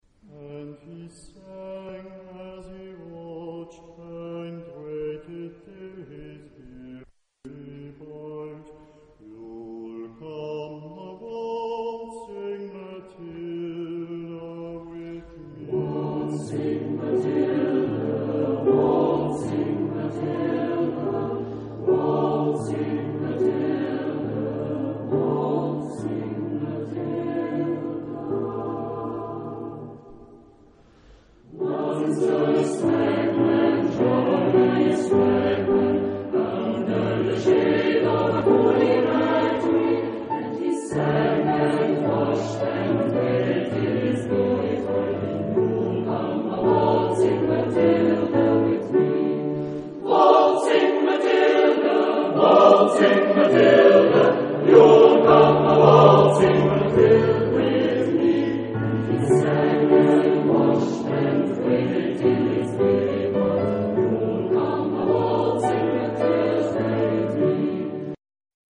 SATB + divisions occasionels (4 voices mixed) ; Full score.